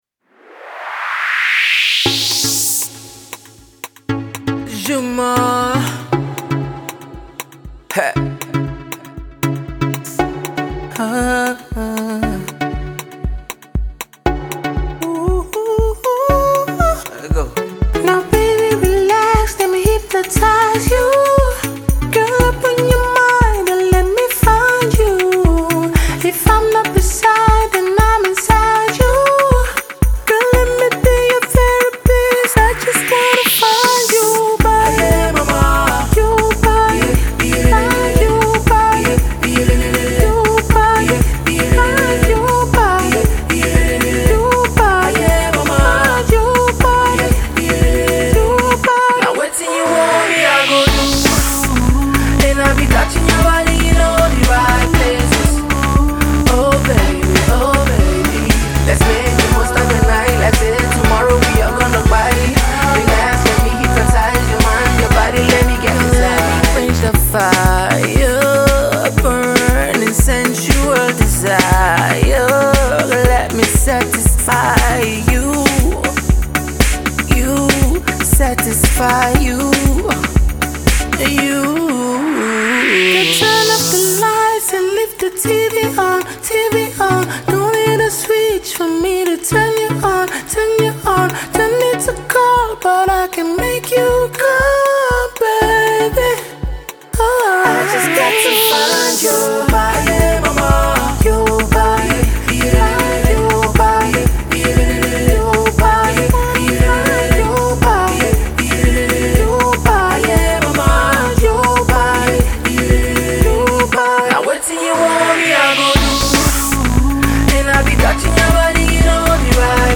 melds genres and vocal styles into one neat package
is heavy